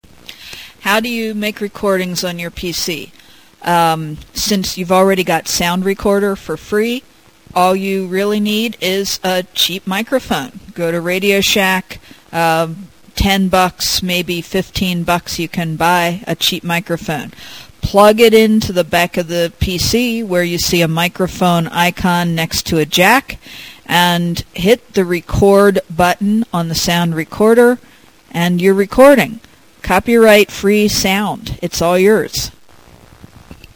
For voice quality equivalent to what you hear on these pages, you can record at 11.025 KHz 8-bit mono.